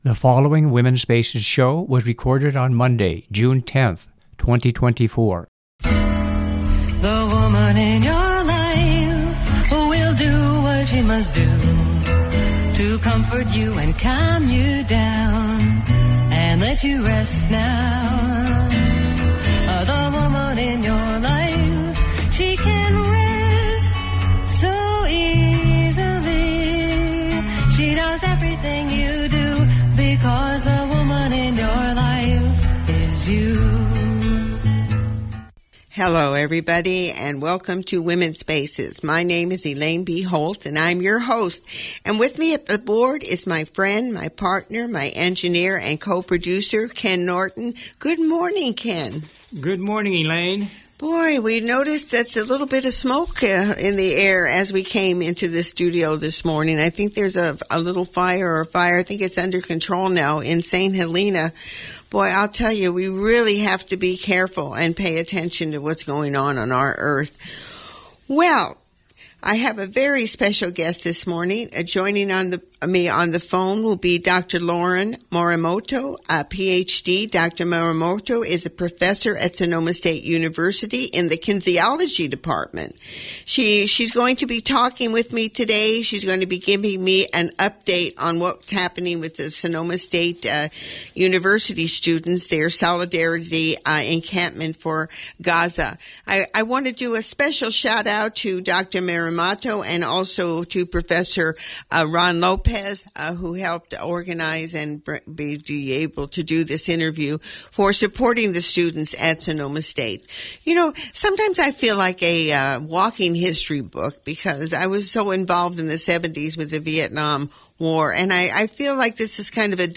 Commentary
I have a special guest this morning.